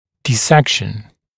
[dɪ’sekʃn] [ди’сэкшн] рассечение; вскрытие